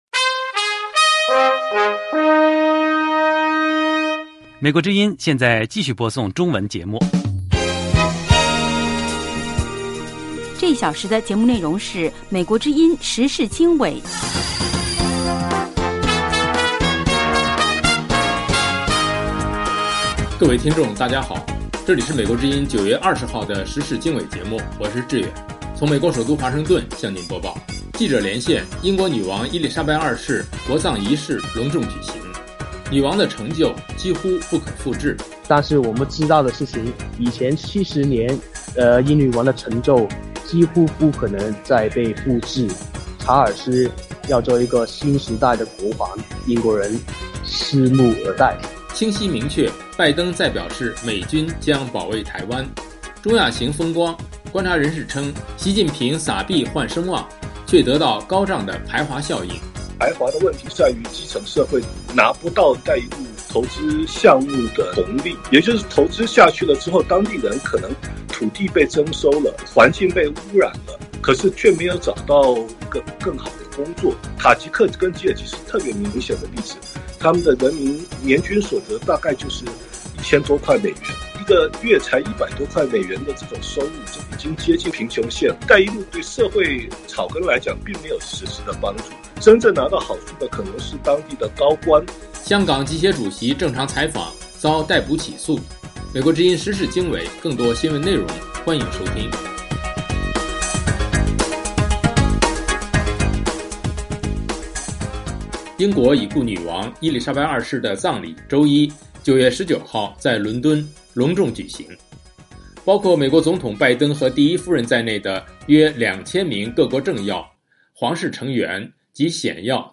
时事经纬(2022年9月20日)：1/记者连线：英国女王伊丽莎白二世国葬隆重举行，女王的成就几乎不可复制。2/拜登再表示美军将保卫台湾。